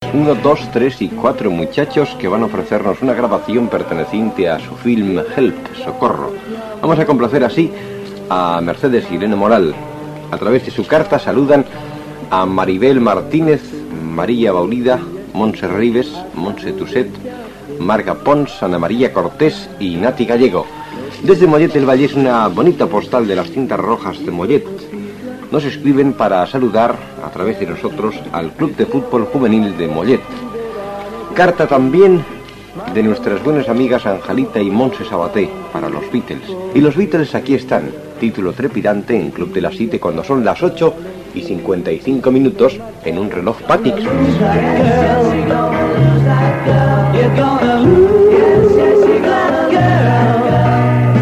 Dedicatòries musicals dels oients, hora i publicitat
Musical